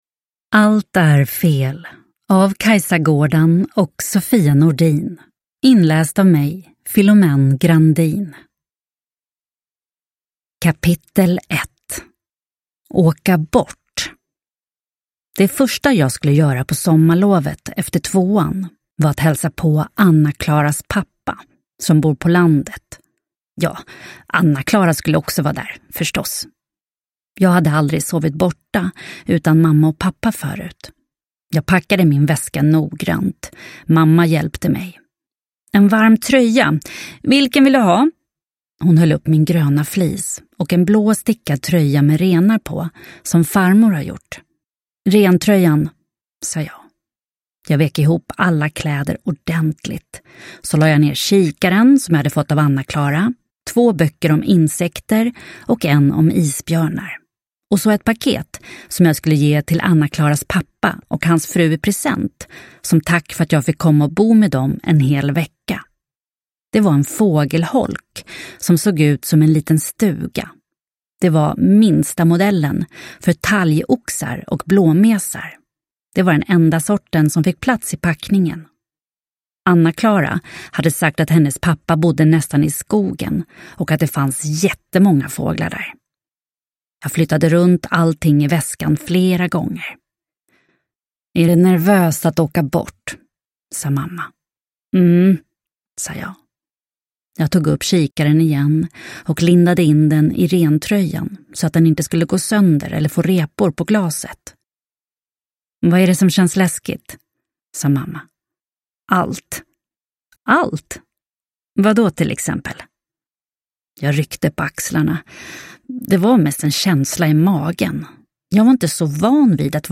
Allt är fel – Ljudbok – Laddas ner